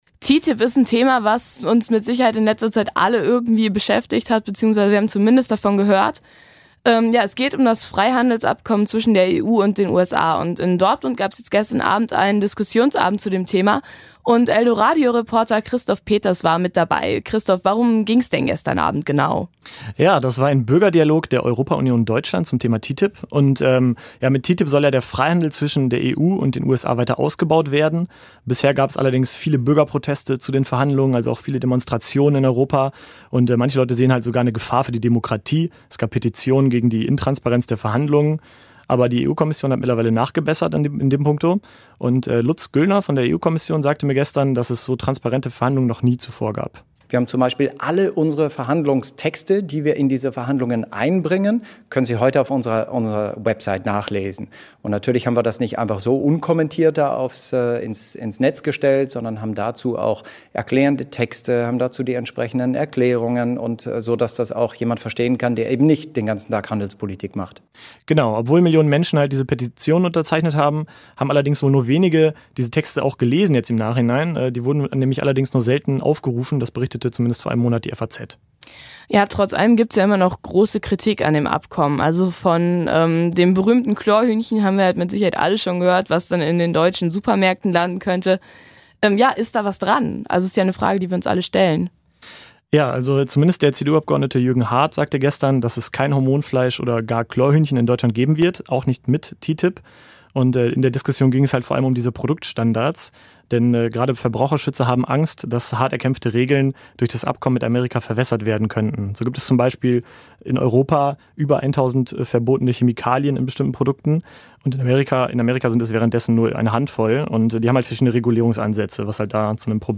Viele werden schon von TTIP gehört haben, dem Freihandelsabkommen zwischen der EU und den USA. In Dortmund gab es gestern einen Diskussionsabend zu dem Thema.